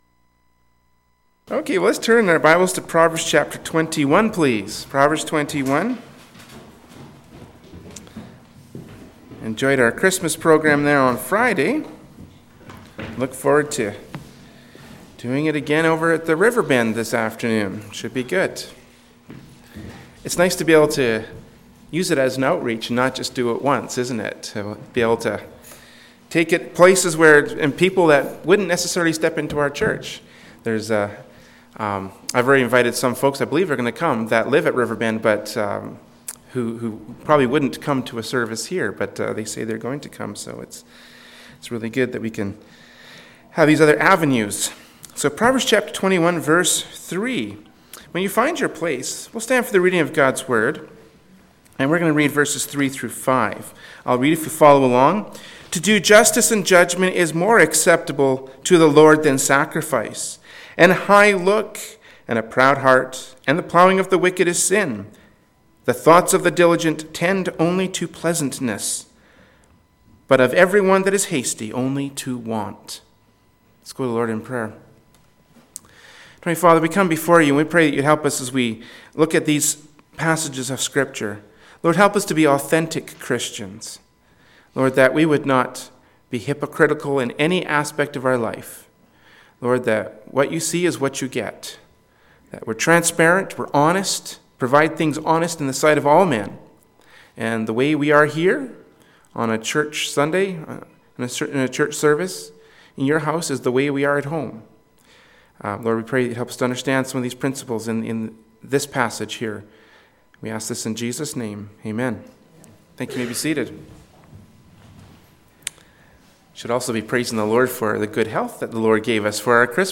“Proverbs 21:1-5” from Sunday School Service by Berean Baptist Church.
Passage: Proverbs 21:1-5 Service Type: Adult Sunday School